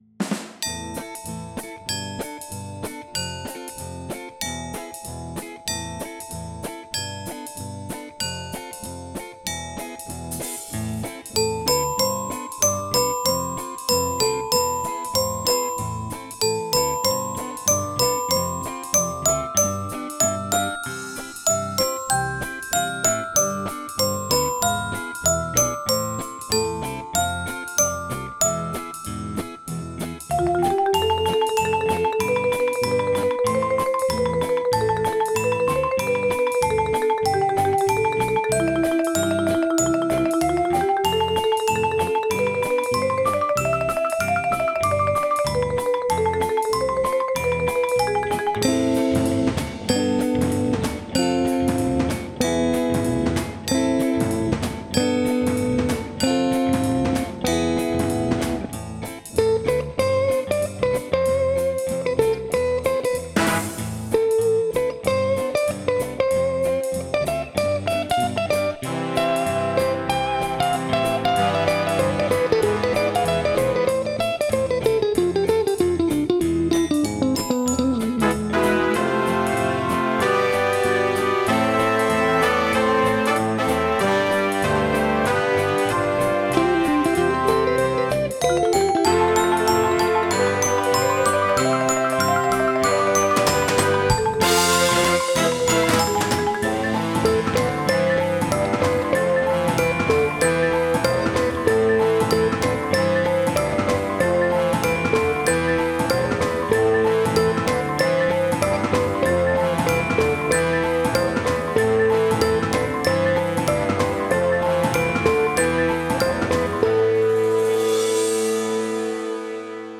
audio soundtrack